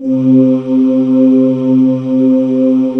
Index of /90_sSampleCDs/USB Soundscan vol.28 - Choir Acoustic & Synth [AKAI] 1CD/Partition C/09-GREGOIRE